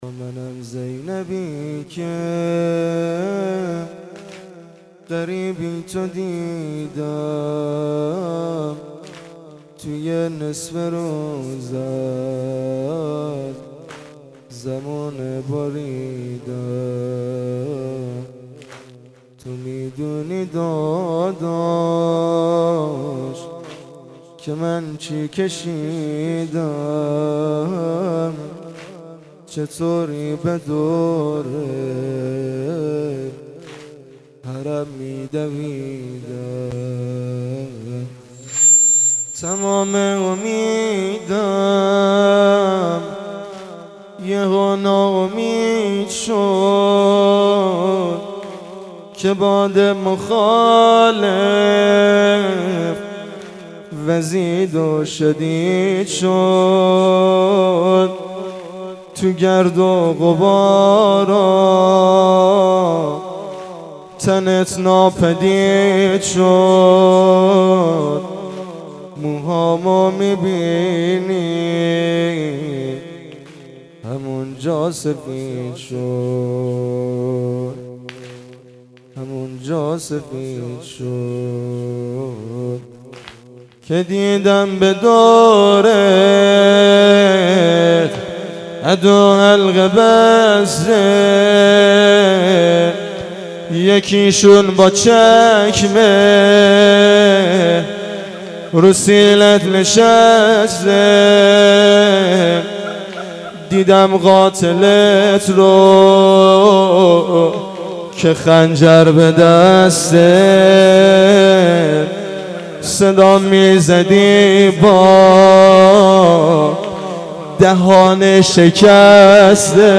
مناسبت : وفات حضرت ام المصائب زینب کبری س
مکان : مشهد مقدس - بلوار پیروزی
مسجد و حسینیه حضرت ابوالفضل ع
==== فایل های صوتی مراسم ====